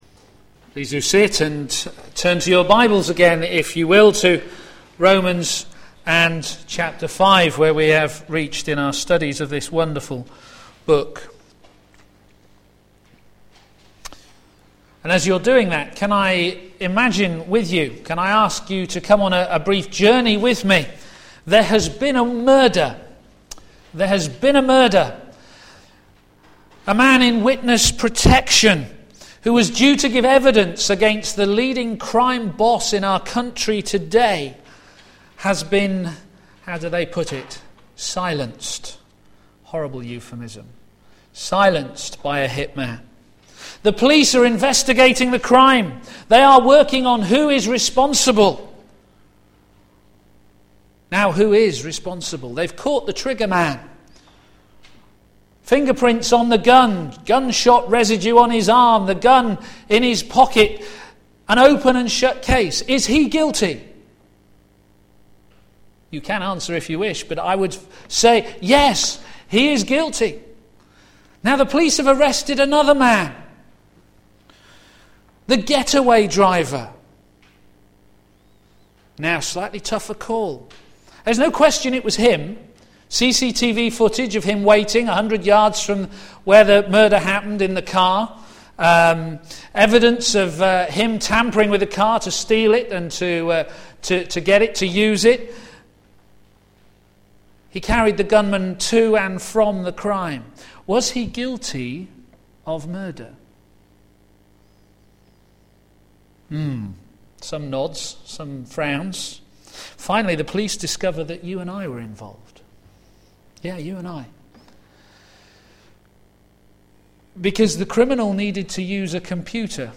p.m. Service
God's righteousness revealed - in imputation Sermon